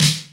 '90s Snappy Acoustic Snare D# Key 122.wav
Royality free snare one shot tuned to the D# note. Loudest frequency: 3368Hz